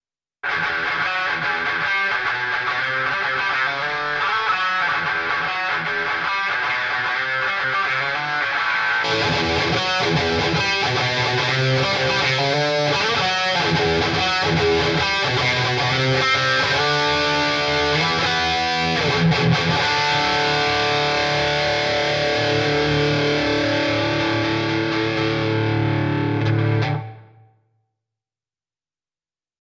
This Amp Clone rig pack is made from a Mesa Boogie JP2C, Mark IV, Mark V, Mark VII and a Mesa Triaxis preamp head.
RAW AUDIO CLIPS ONLY, NO POST-PROCESSING EFFECTS